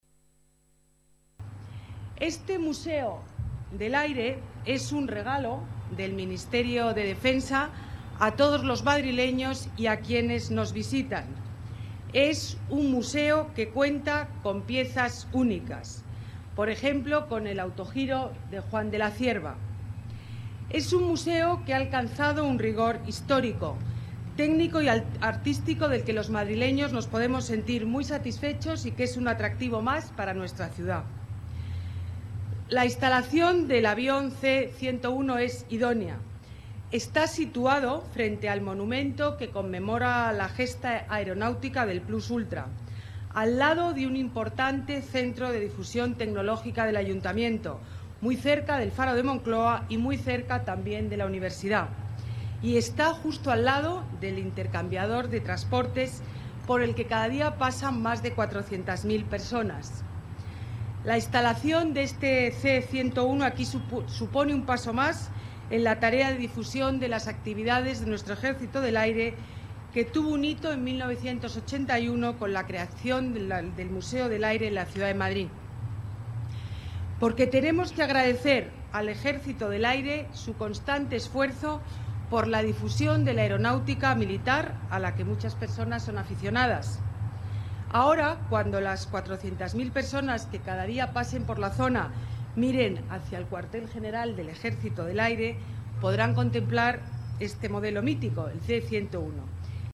Nueva ventana:Declaraciones de Ana Botella, alcaldesa de Madrid